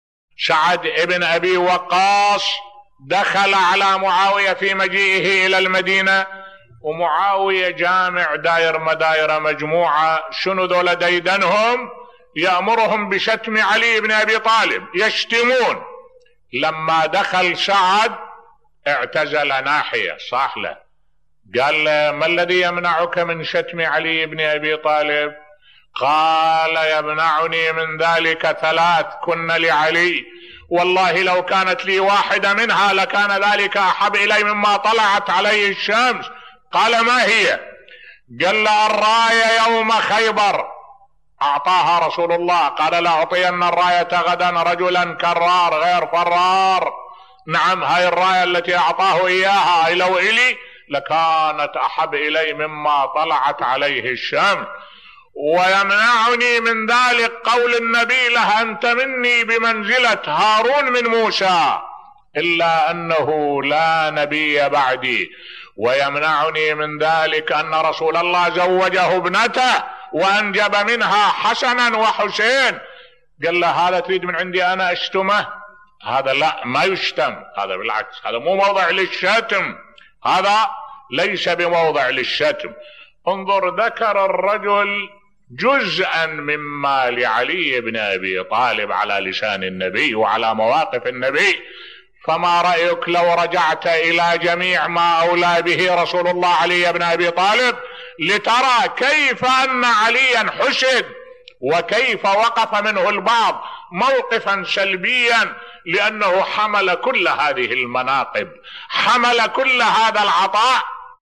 ملف صوتی اراد معاوية من سعد بن أبي وقاص أن يشتم علي ؟ ماذا قال سعد ؟ بصوت الشيخ الدكتور أحمد الوائلي